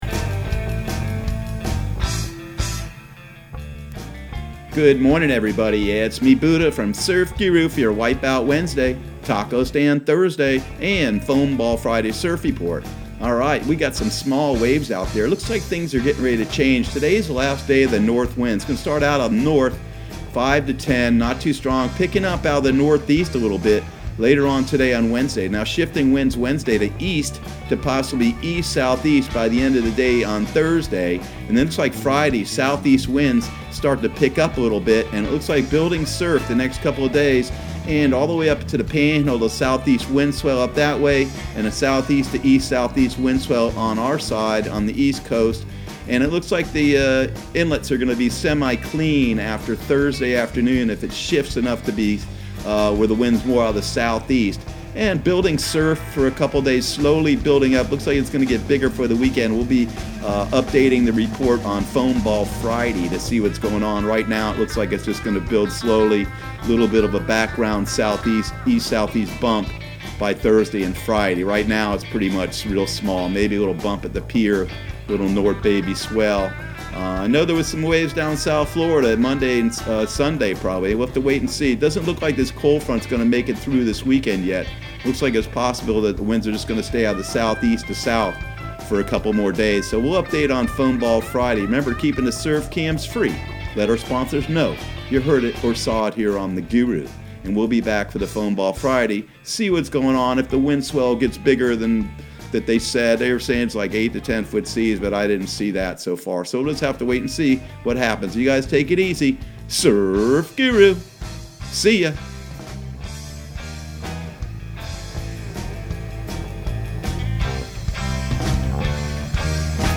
Surf Guru Surf Report and Forecast 01/08/2020 Audio surf report and surf forecast on January 08 for Central Florida and the Southeast.